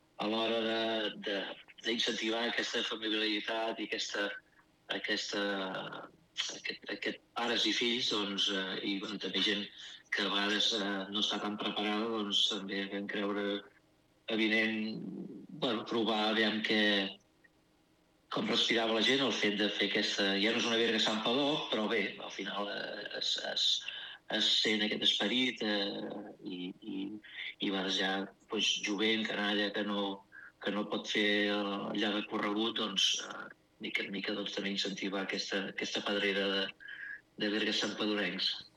Com sempre, els participants podran escollir entre dos circuits: el primer per a caminants i corredors, de 52 quilòmetres; i el segon per a ciclistes, de 57 quilòmetres. Ho explicava fa uns dies a l’informatiu de Ràdio Santpedor